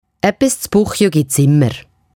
Walliser Titsch - Äppis z puchiu gits …